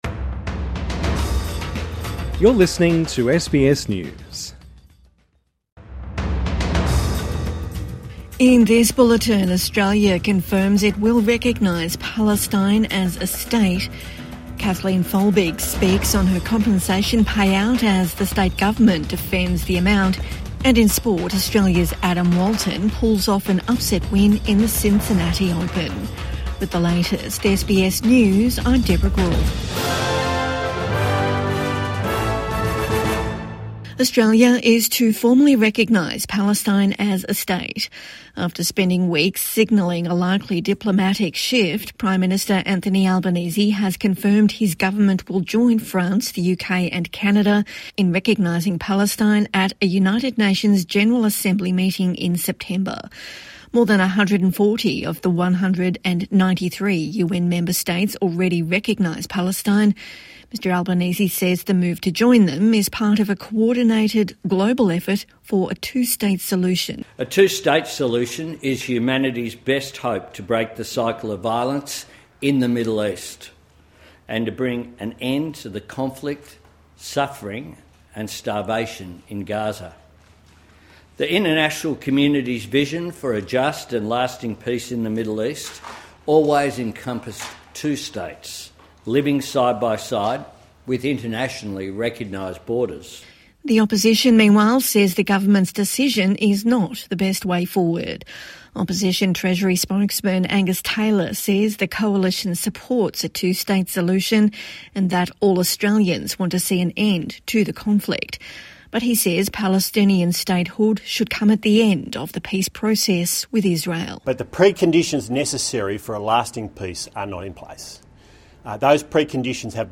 Australia confirms it will recognise Palestinian statehood | Evening News Bulletin 11 August 2025